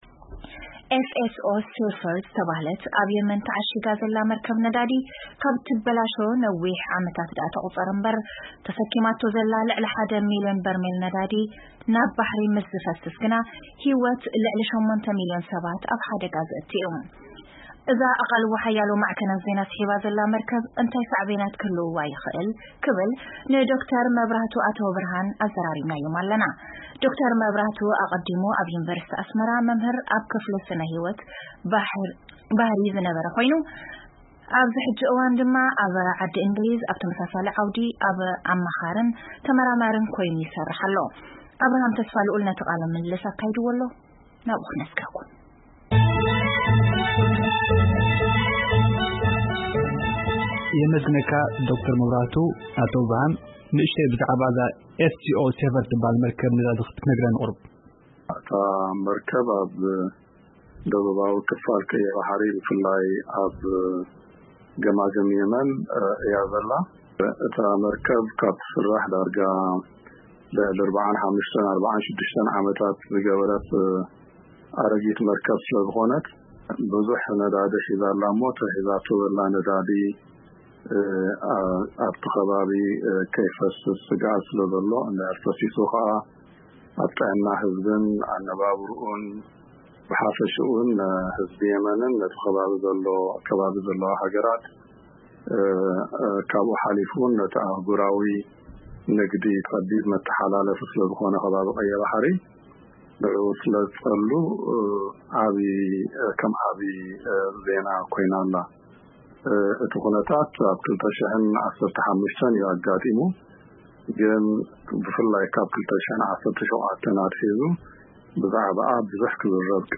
ቃለ መጠይቕ ምስ ተመራማሪ ስነ-ህይወት ባሕሪ ኣብ ጉዳይ ኣብ ገማግም ቀይሕ ባሕሪ ተዓሺጋ ንነዊሕ ዓመታት ደው ዝበለት መርከብ